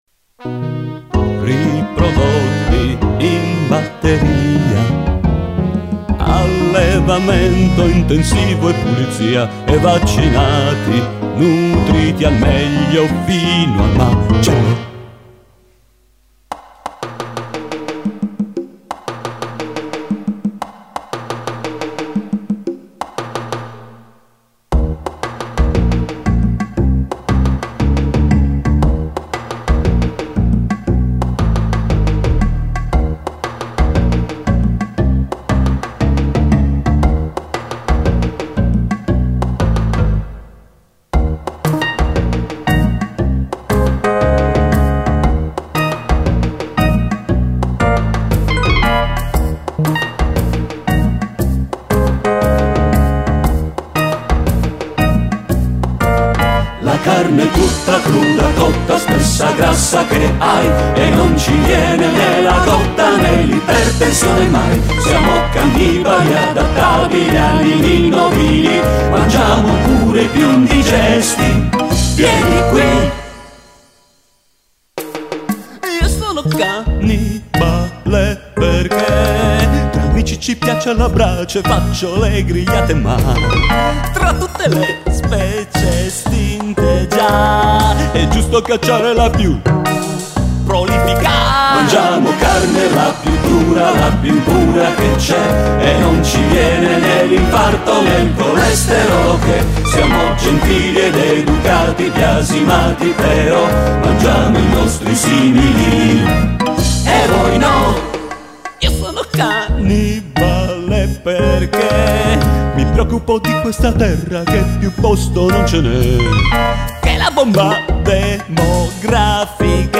spettacolo musicale tragicomico (come tutto è)